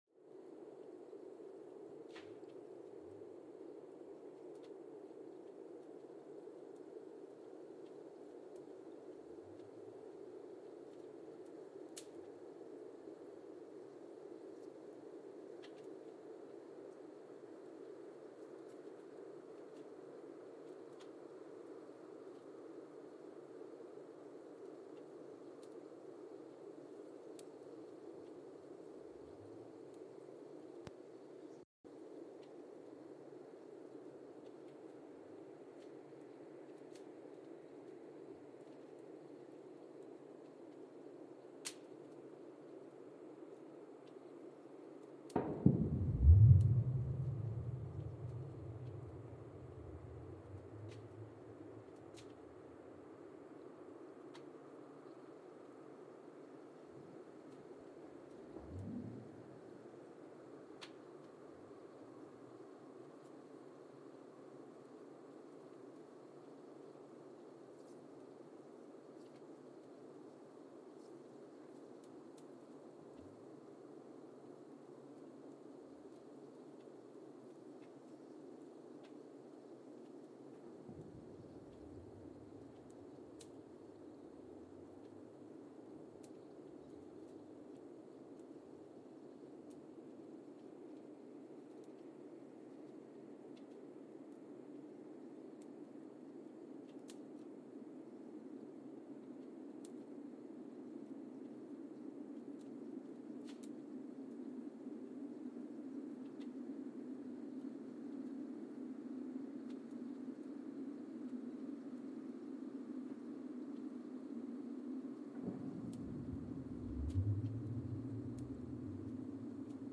Mbarara, Uganda (seismic) archived on November 23, 2017
Sensor : Geotech KS54000 triaxial broadband borehole seismometer
Speedup : ×1,800 (transposed up about 11 octaves)
Loop duration (audio) : 05:36 (stereo)